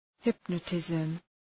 Προφορά
{‘hıpnə,tızm}